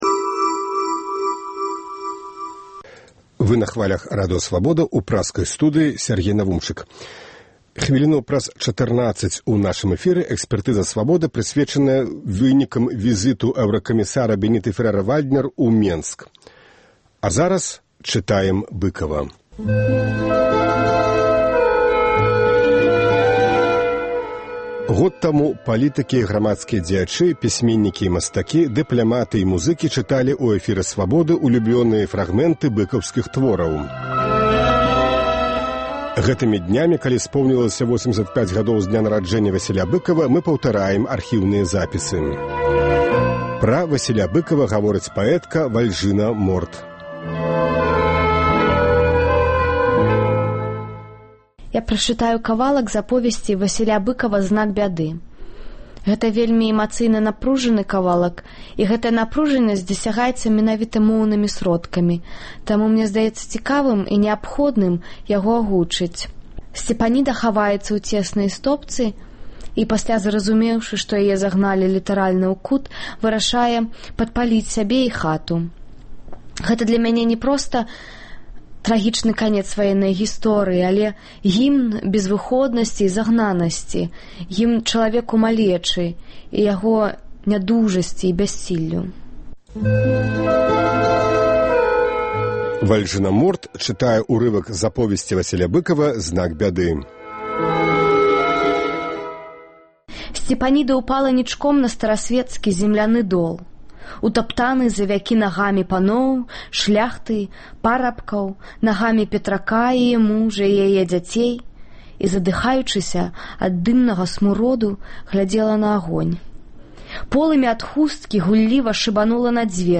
Год таму палітыкі і грамадзкія дзеячы, пісьменьнікі і мастакі, дыпляматы і музыкі чыталі ў эфіры Свабоды ўлюблёныя фрагмэнты быкаўскіх твораў. Гэтымі днямі, калі споўнілася 85-гадоў з дня нараджэньня Васіля Быкава, мы паўтараем архіўныя запісы. Сёньня Быкава чытаюць паэтка Вальжына Морт і паэт Уладзімер Някляеў.